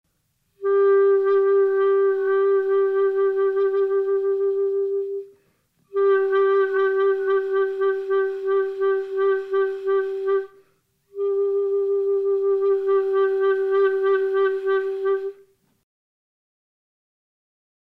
Vibrato
Pitch vibrato is achieved by the clarinetist through fluctuations of jaw (or lip) pressure on the reed.
Depth (pitch) can also be indicated (as Paul Zonn does in Revolution ).